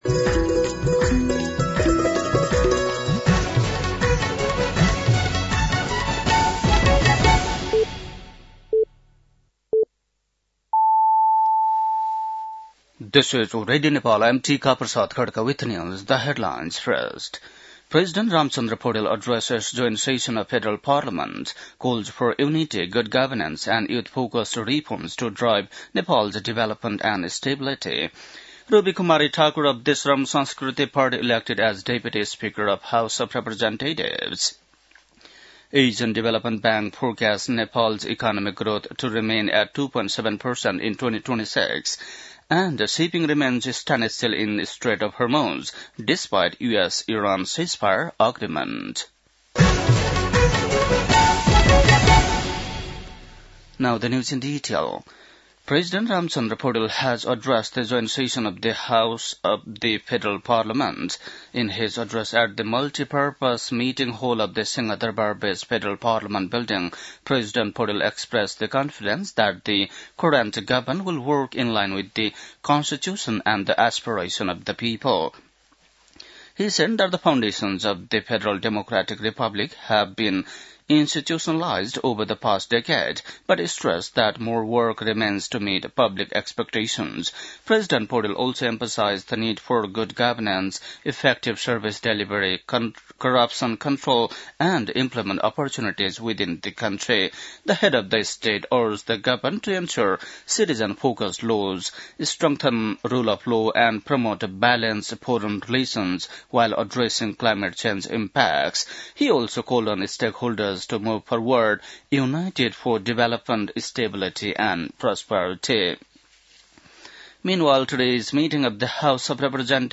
बेलुकी ८ बजेको अङ्ग्रेजी समाचार : २७ चैत , २०८२